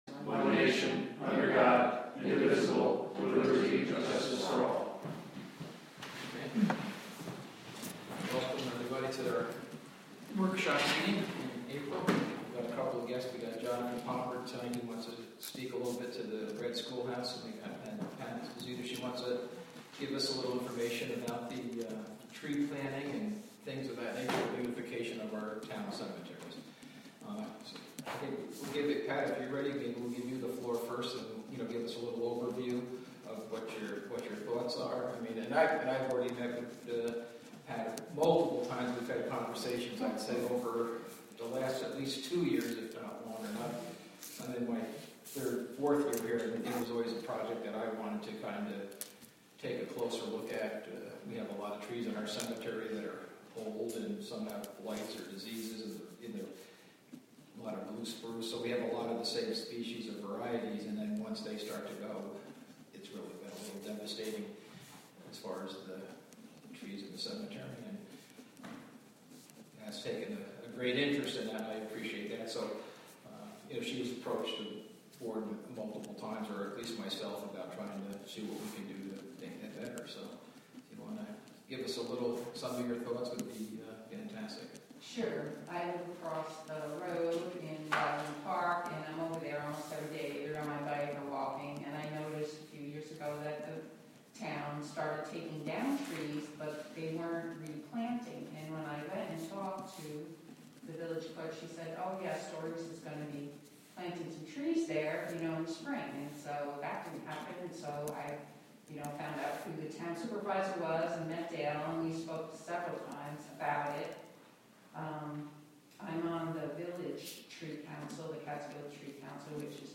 The Town of Catskill holds their monthly meeting.
WGXC is partnering with the Town of Catskill to present live audio streams of public meetings.